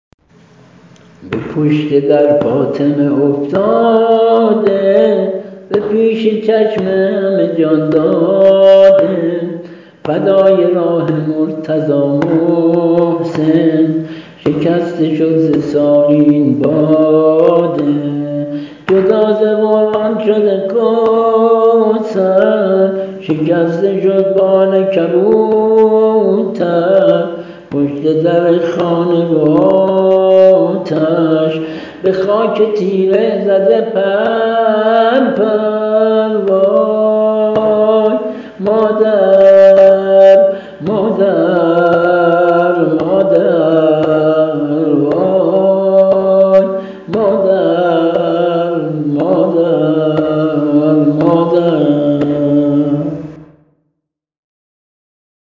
◾نوحه‌ی سینه‌زنی
◾سبک و ملودی‌جدید